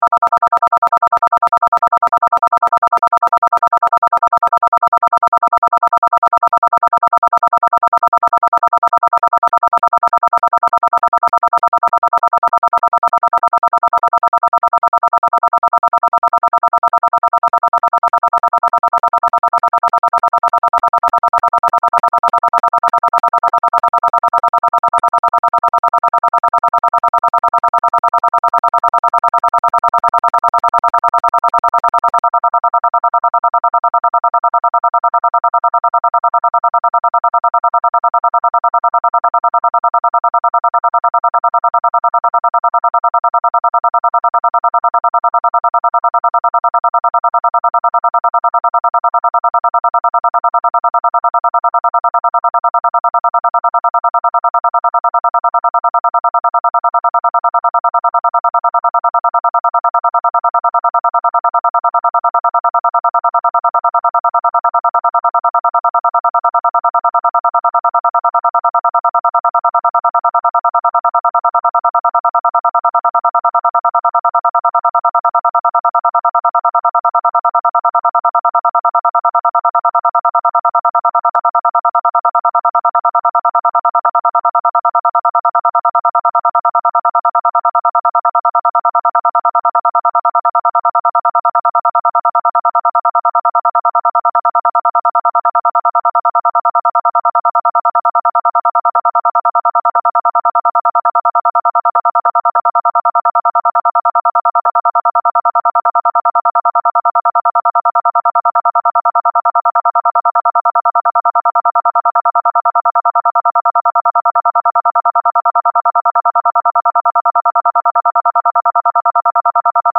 Arecibo_message_DTMF.mp3